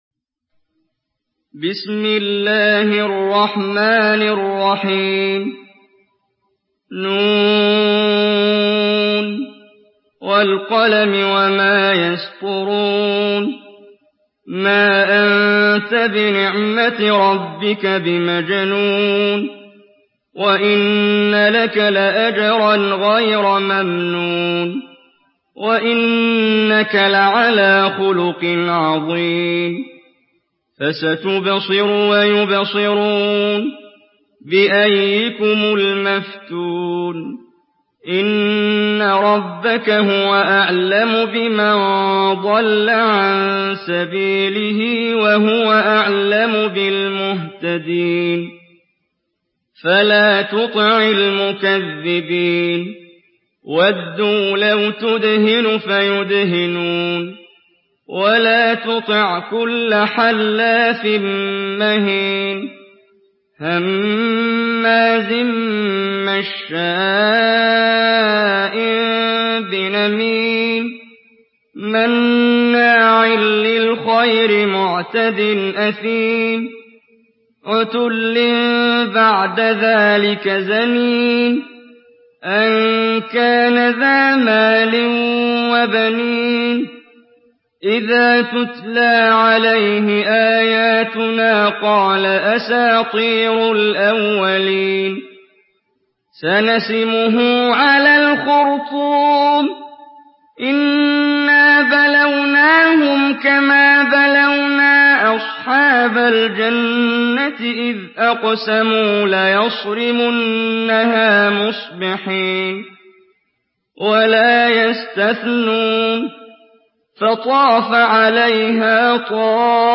Surah Al-Qalam MP3 in the Voice of Muhammad Jibreel in Hafs Narration
Surah Al-Qalam MP3 by Muhammad Jibreel in Hafs An Asim narration.
Murattal Hafs An Asim